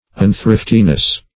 Search Result for " unthriftiness" : The Collaborative International Dictionary of English v.0.48: Unthriftiness \Un*thrift"i*ness\, n. The quality or state or being unthrifty; profuseness; lavishness.